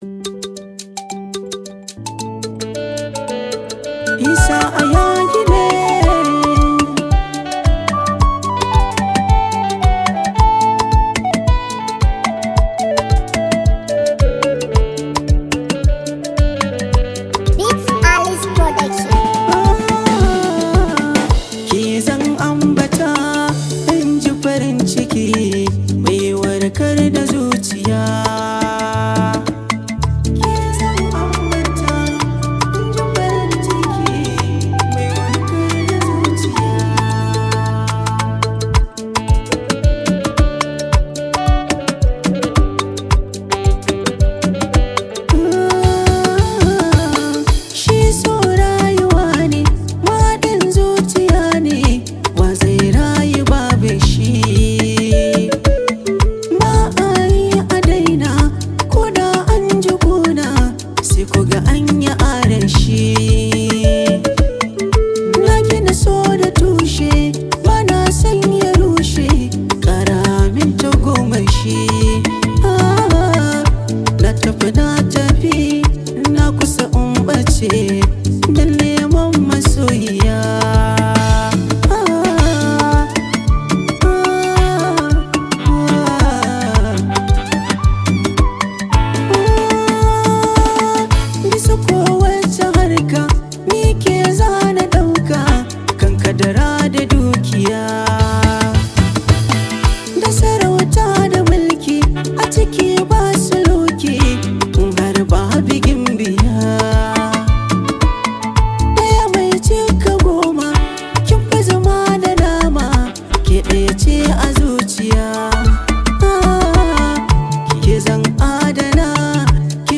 Wakokin Hausa
Hausa singer
Known for his soulful voice and unique style
delightfully rhythmic song
rich and vibrant instrumentals